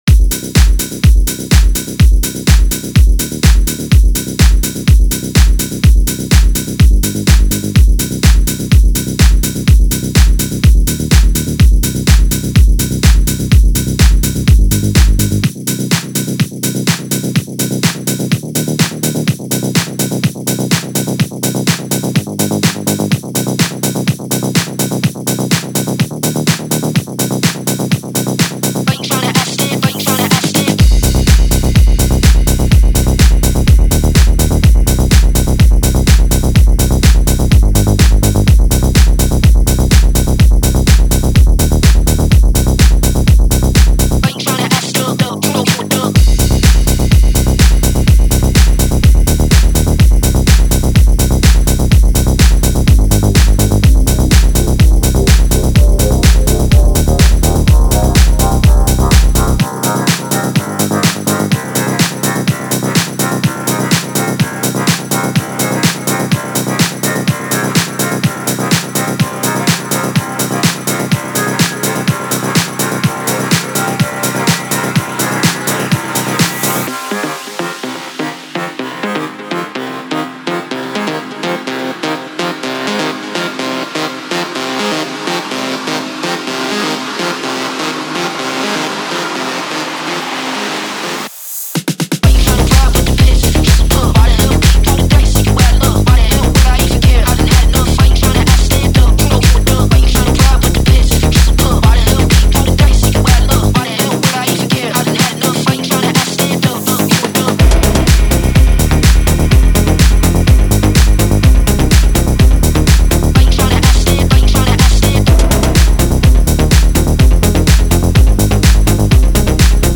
• Жанр: Electronic, House